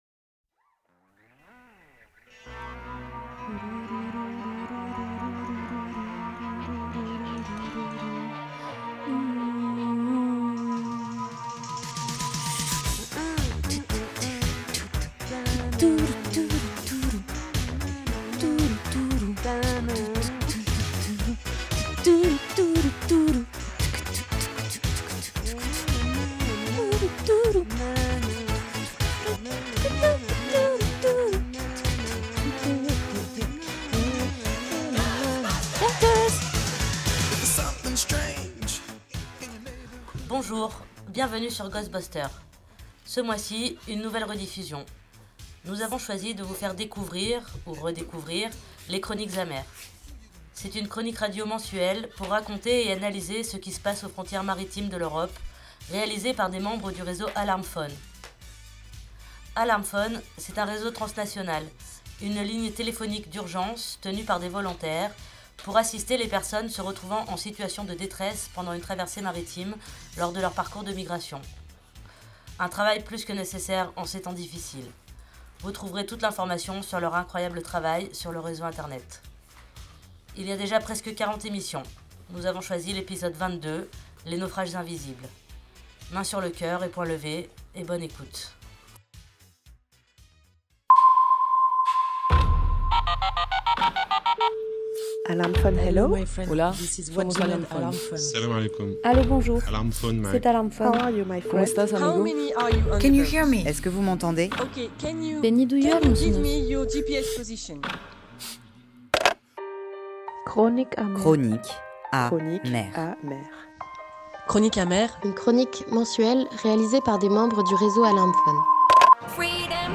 C’est une chronique radio mensuelle pour raconter et analyser ce qui se passe aux frontières maritimes de l’Europe, réalisée par des membres du réseau AlarmPhone.
Invité(s) : le réseau AlarmPhone